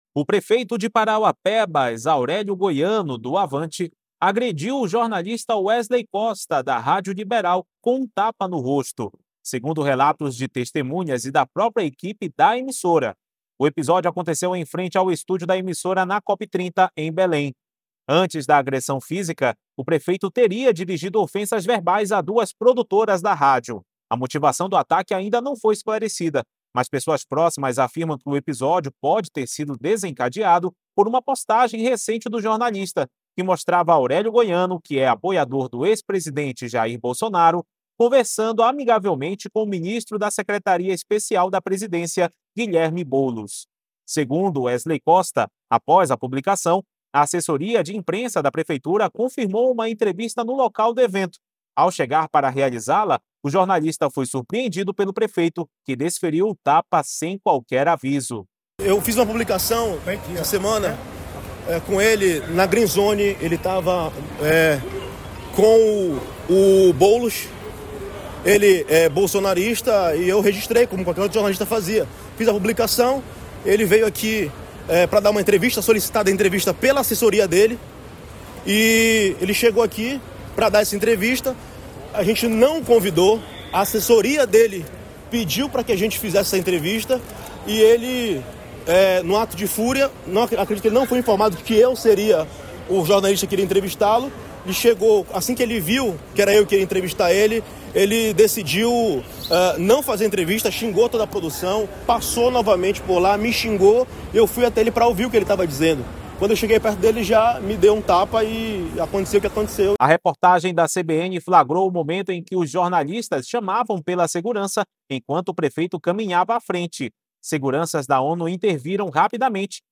O episódio aconteceu em frente ao estúdio da emissora na COP30, em Belém.
A reportagem da CBN flagrou o momento em que os jornalistas chamavam pela segurança enquanto o prefeito caminhava À frente.